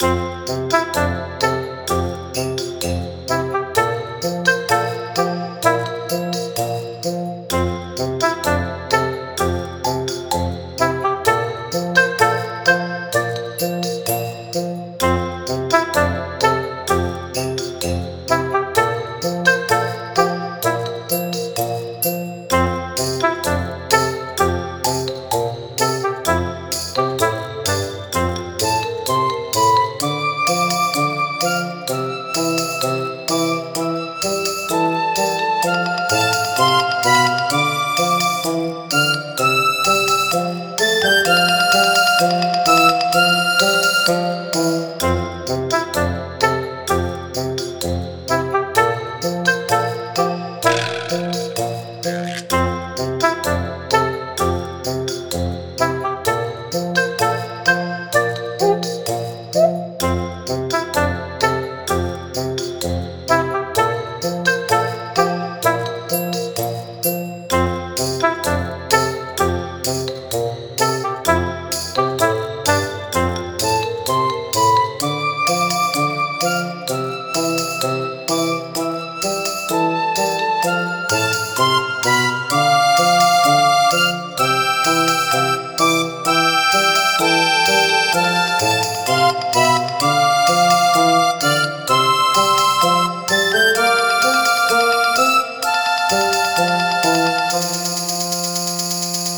明るい音色の中に少しの戸惑いや慎重さが感じられ「挑戦」というテーマにぴったりの空気感を漂わせています。
そんな風景が、優しいメロディと穏やかなハーモニーの中に浮かびます。
• ステレオ音源、サンプリングレート：44.1kHz
• 構成：Aメロ～Bメロと続き、ラストで穏やかに終息するワンループ構成
• 楽器編成（推定）：マリンバやベル系の可愛らしい音色を中心にオーボエ、ピッコロ、軽やかなリズム楽器がサポート。
初心者や子ども向けの世界観を壊さないやさしいサウンド設計が特徴です。